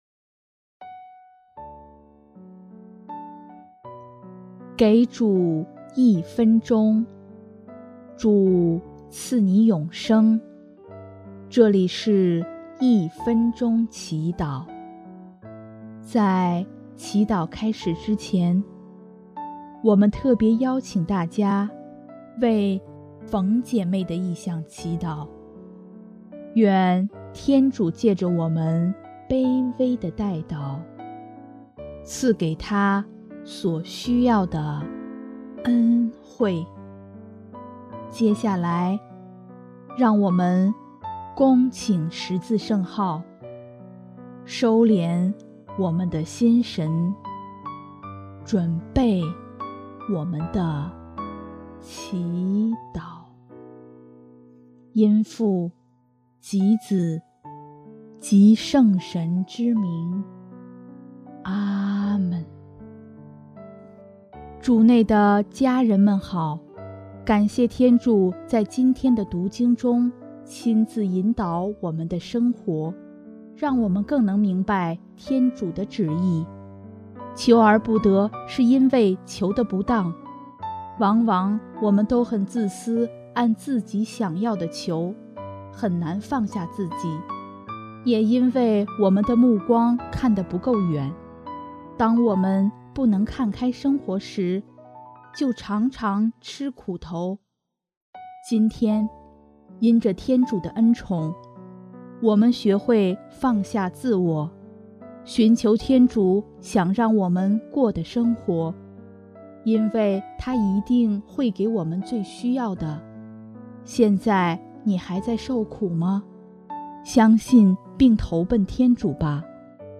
【一分钟祈祷】|5月21日 天主一定会给我们更好的